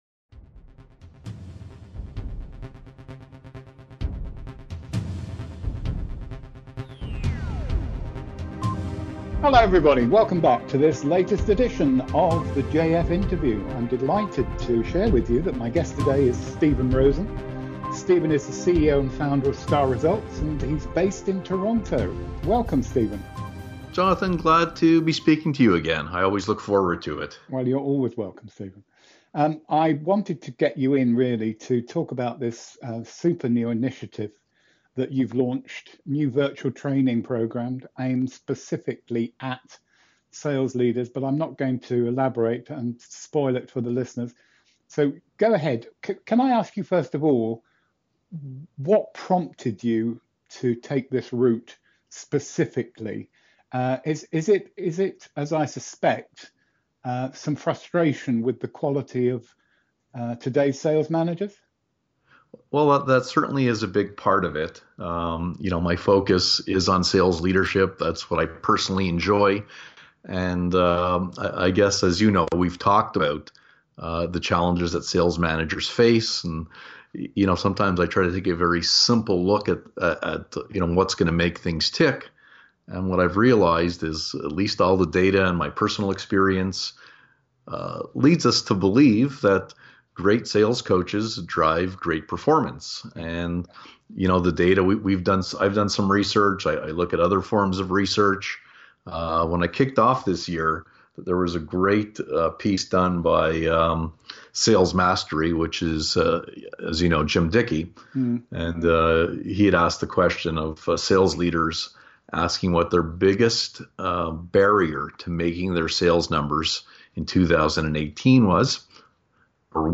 Category: Interview, Sales Coaching, Sales Management